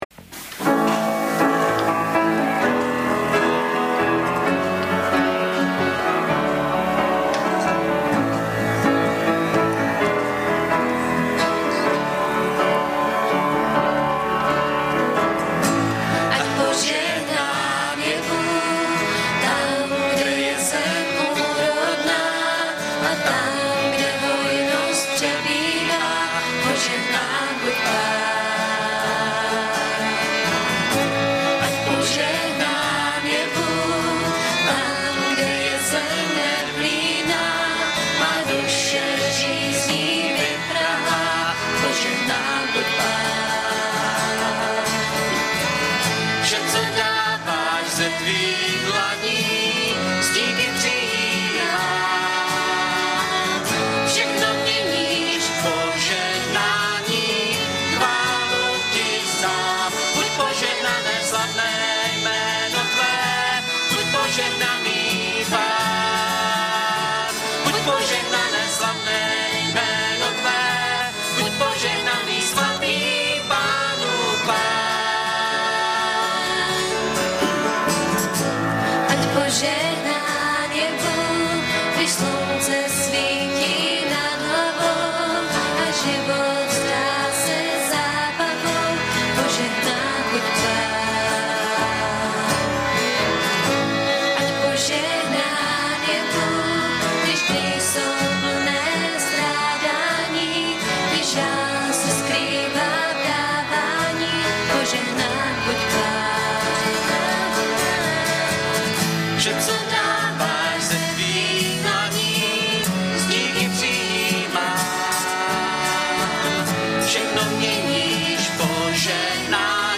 Audiozáznam kázání